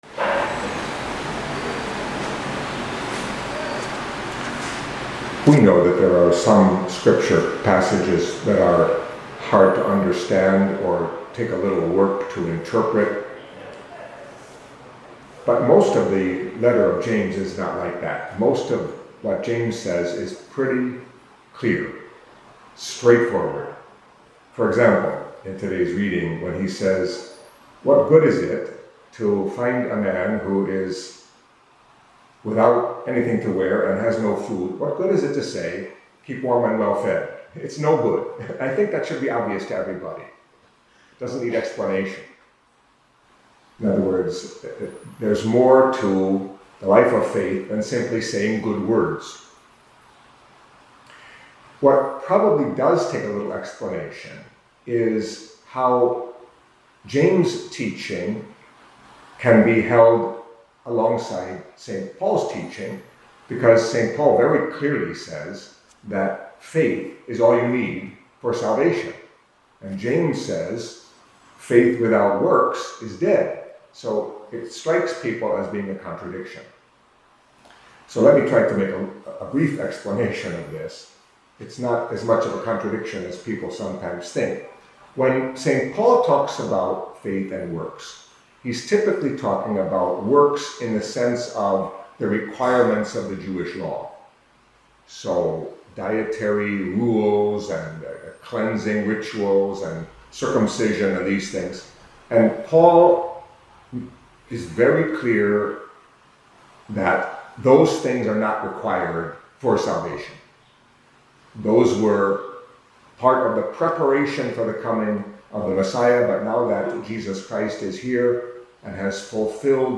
Catholic Mass homily for Friday of the 6th Week in Ordinary Time